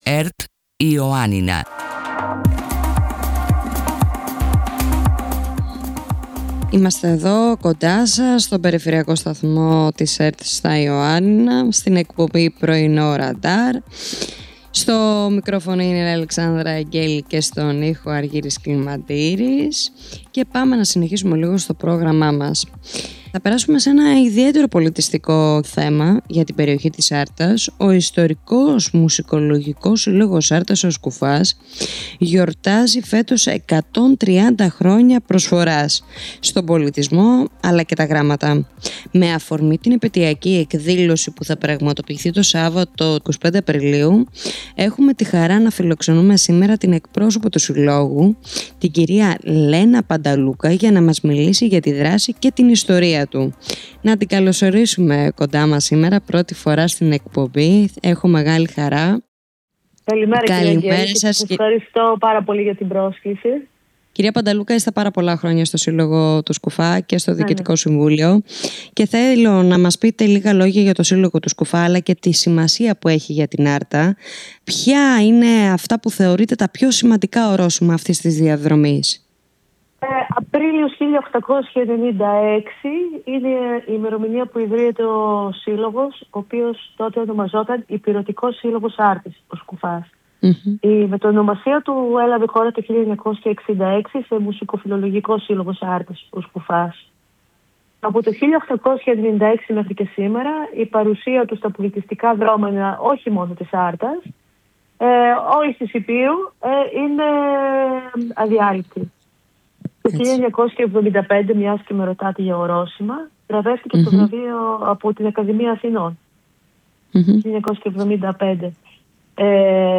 Πρωινο Ρανταρ Συνεντεύξεις